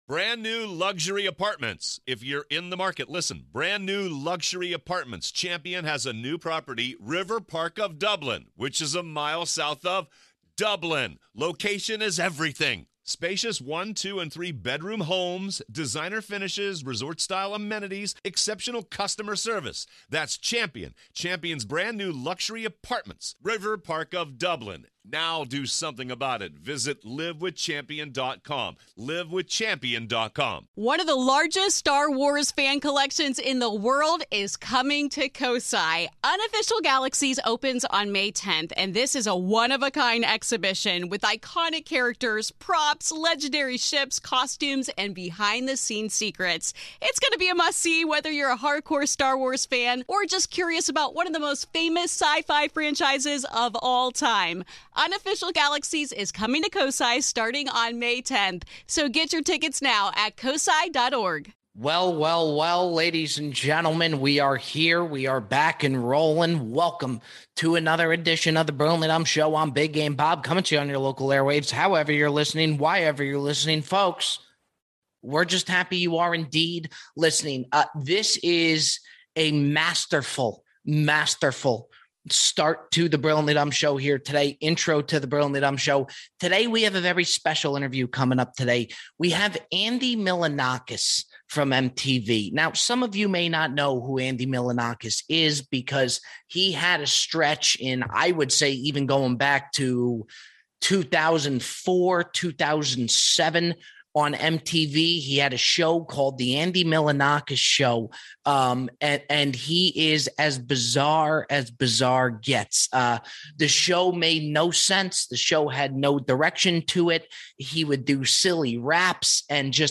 Andy Milonakis joins the show to talk about his rise to fame, if the Super Bowl is actually gay, MTV and much much more. A peculiar voicemail about tuna closes the show.